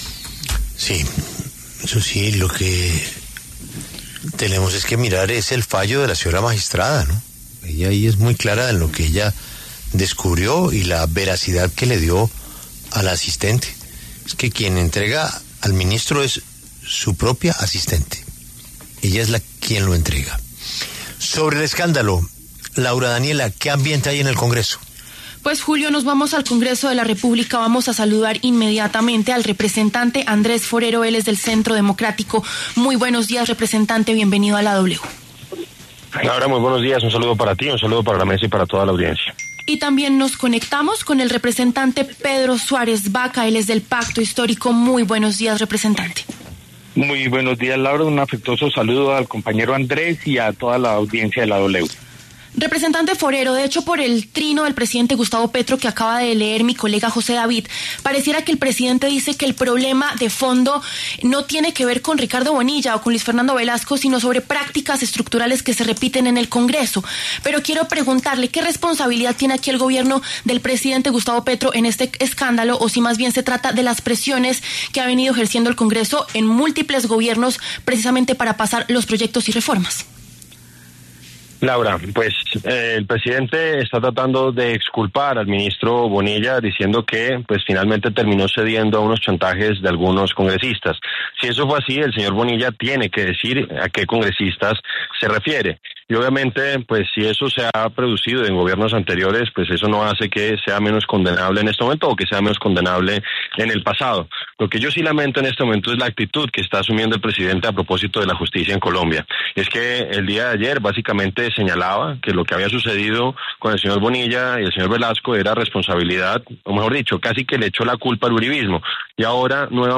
Por los micrófonos de La W, pasaron los congresistas Andrés Forero, del Centro Democrático, y Pedro Suárez Vacca, del Pacto Histórico.